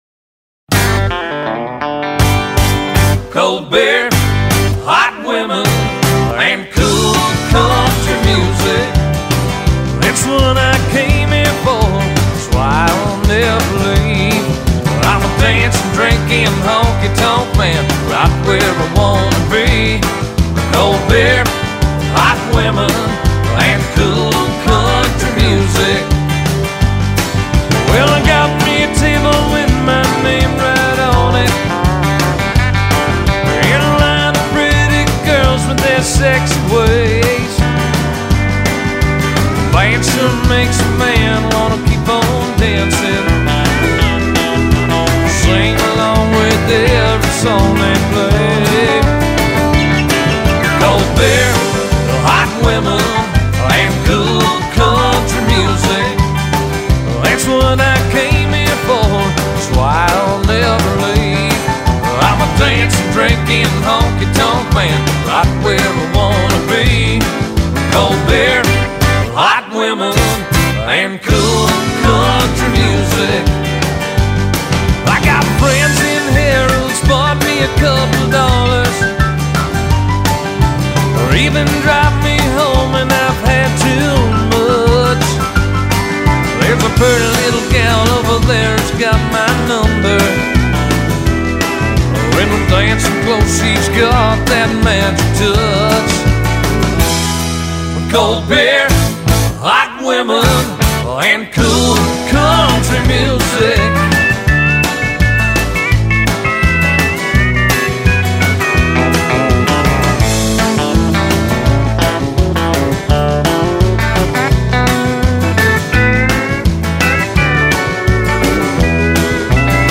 风格：Country，Country-Rock，Alternative Country
这张专辑属于另类乡村音乐